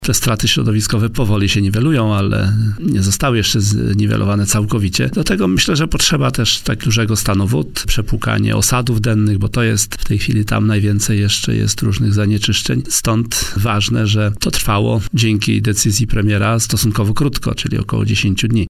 – Potrzeba jeszcze czasu, aby całkowicie zniwelować straty środowiskowe – podkreślił na antenie Radia Warszawa w Poranku Siódma9 Minister Środowiska Henryk Kowalczyk.